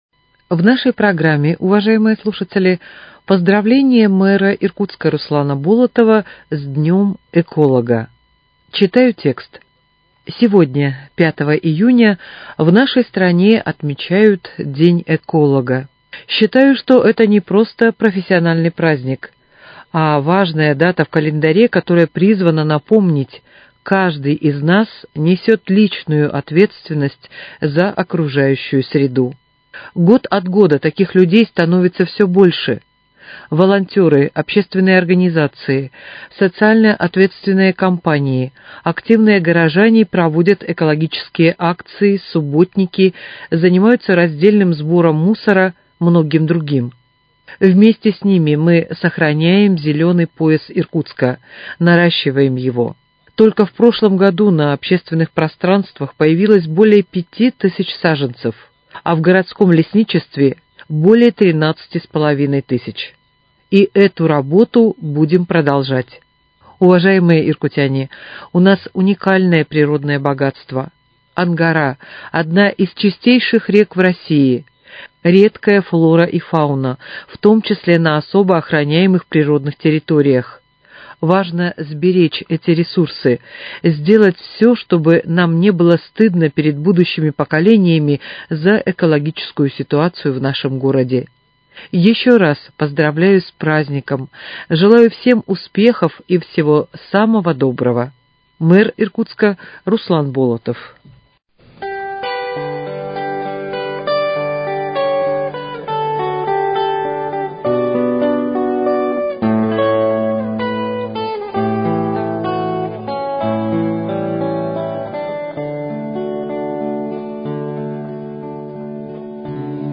Поздравление мэра Иркутска Руслана Болотова с Днём эколога